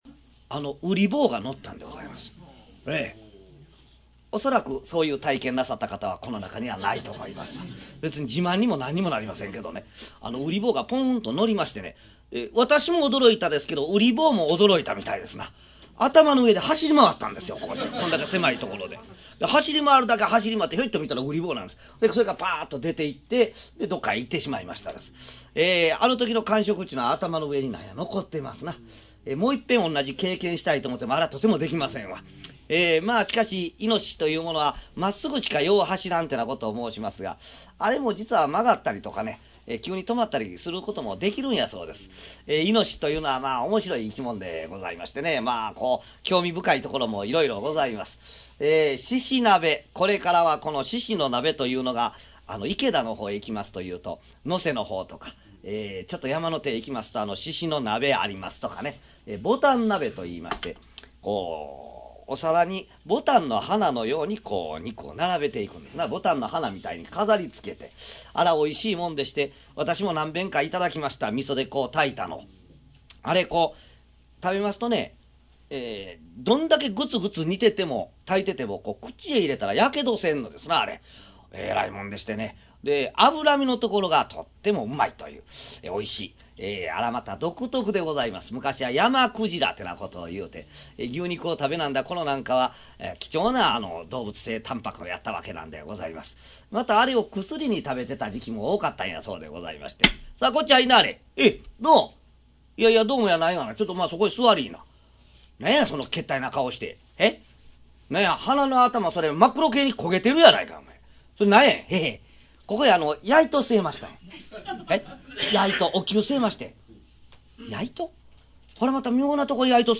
「猫間川寄席」の雰囲気を、そのままで！
約15年間、大阪玉造さんくすホールで、毎月開催されている「猫間川寄席」での、四代目桂文我の口演を収録した落語集。 書籍版「桂文我 上方落語全集」に掲載したネタを、ライブ公演の録音で楽しみ、文字の落語と、実際の口演との違いを再確認していただければ幸いです。